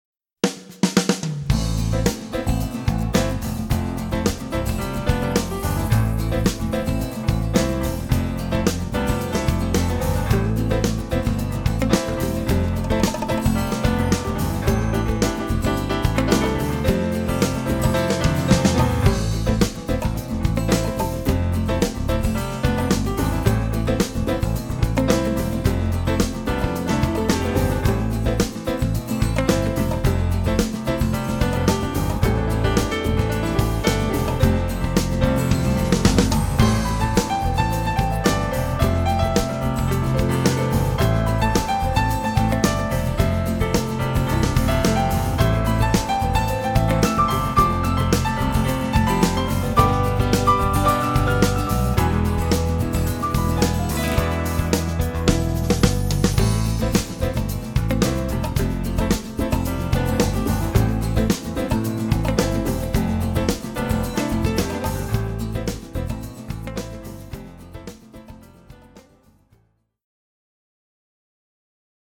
ヒーリングＣＤ